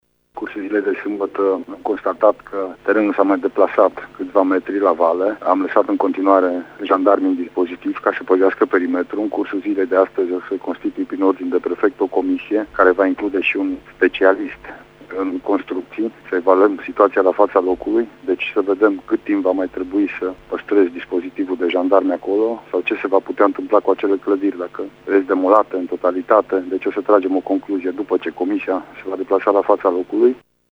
Prefectul judeţului Mureş, Lucian Goga, a spus pentru RTM că în cursul zilei de azi se va constitui o comisie care va decide, după analizarea la faţa locului a situaţiei, ce se va întâmpla cu casele afectate: